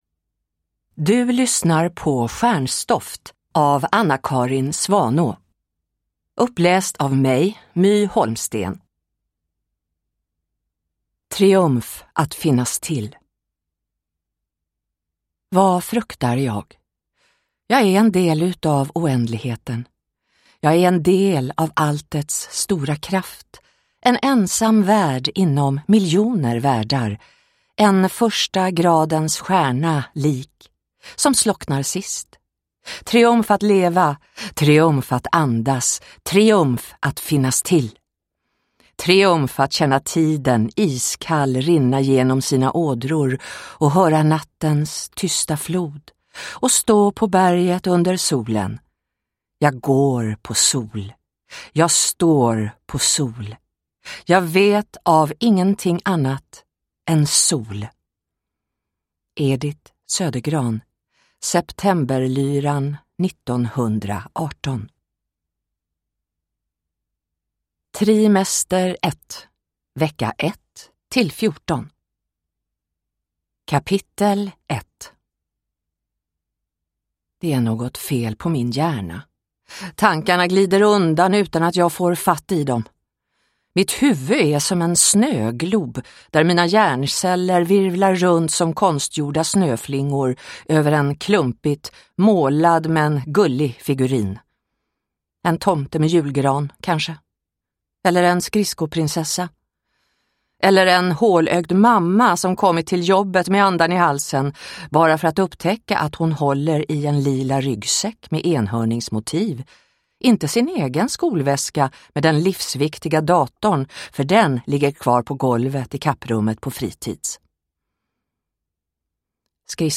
Stjärnstoft (ljudbok) av Anna-Carin Svanå